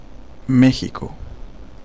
México (phát âm tiếng Tây Ban Nha: [ˈmexiko]
Trong tiếng Tây Ban Nha tên gọi này được phát âm là /ˈmexiko/ (Mê-khi-cô).
Es-mx-México.ogg.mp3